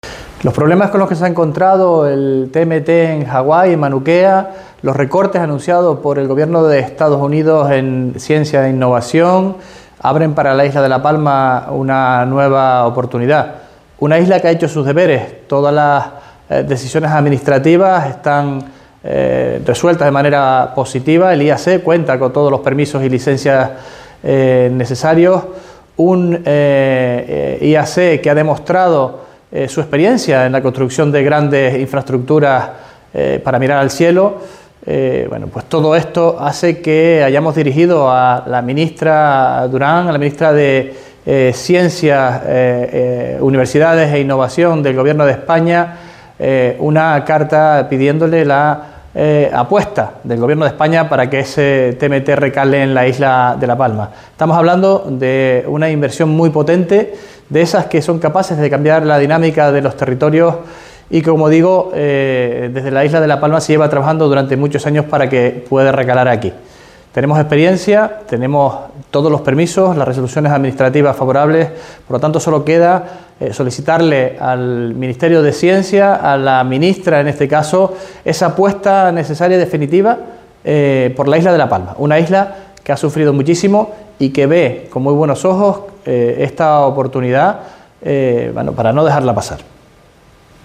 Declaraciones Sergio Rodríguez TMT audio.mp3